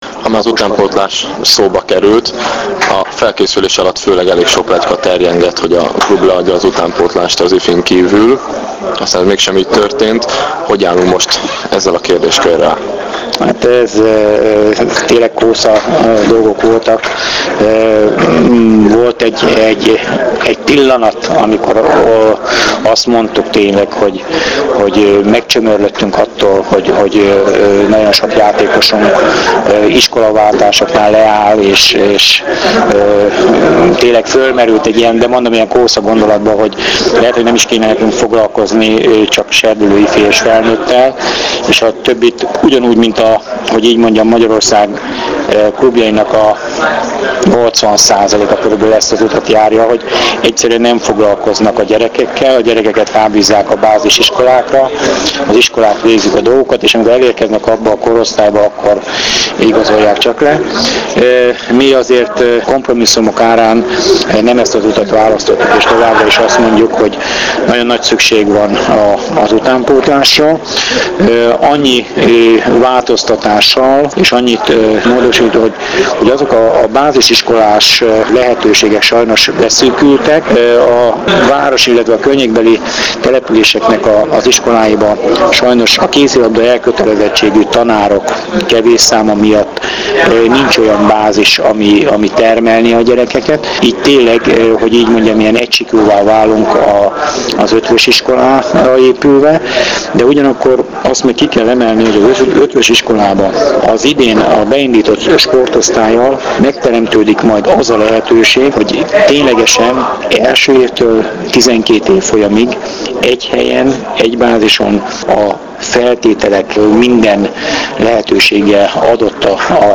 Az interjú mp3-ban >>>